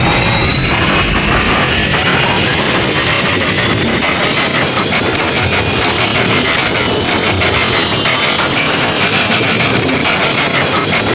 aheli_crashing_loop1.wav